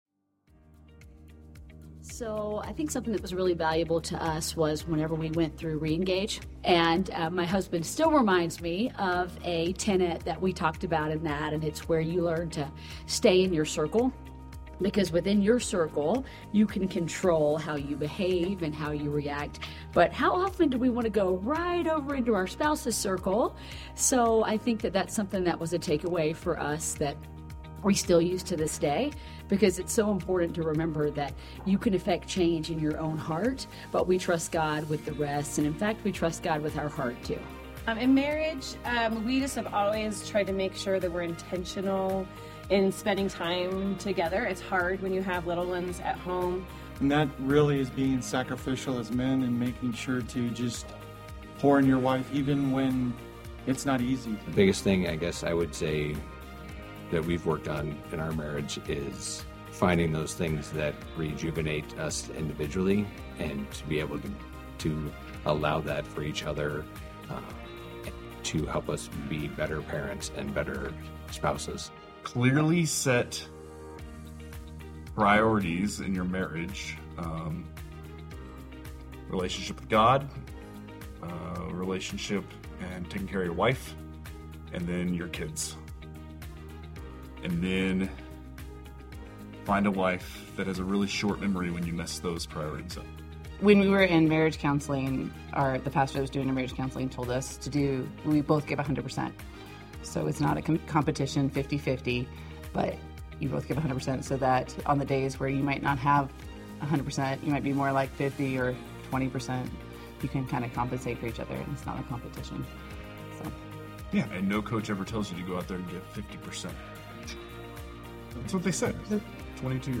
A message from the series "Riding the Rapids." The importance of parenting with love and teamwork, focusing on grace over perfection in parenting and trusting in God amid the chaos.